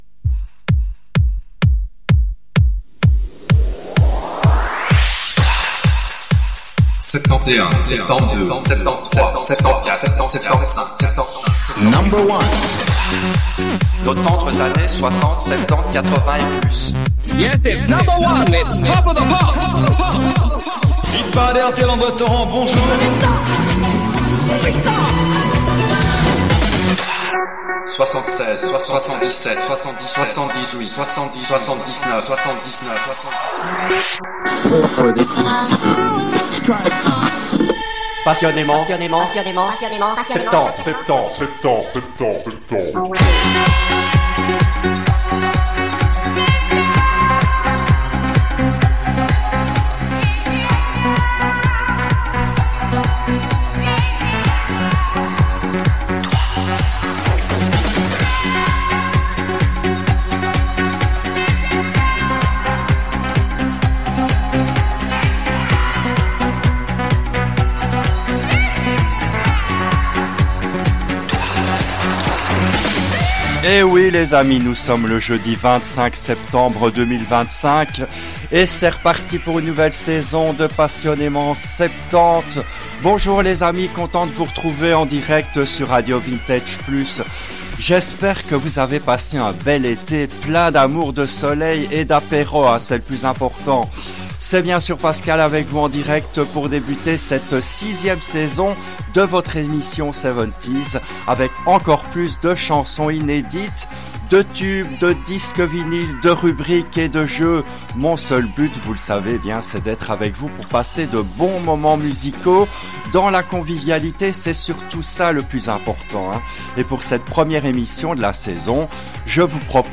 Cette émission a été diffusée en direct le jeudi 30 novembre 2023 à 10h depuis les studios belges de RADIO RV+.